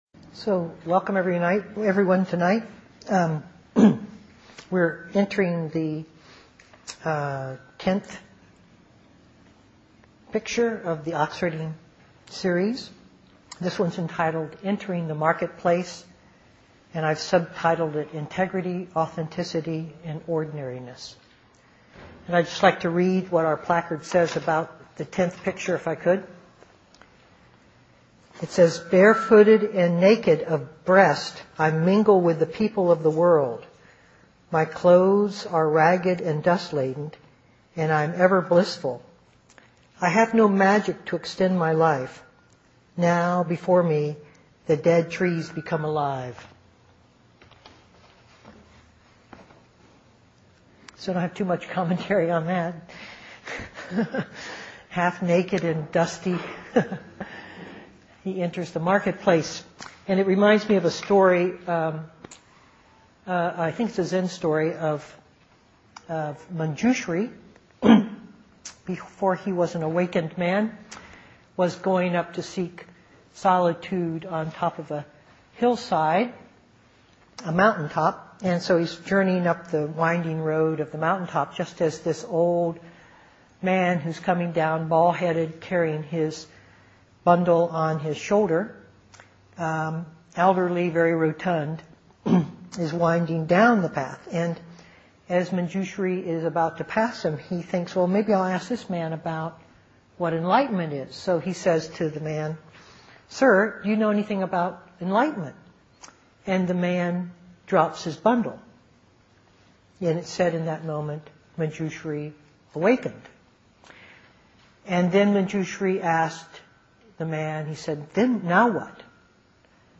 2008-09-09 Venue: Seattle Insight Meditation Center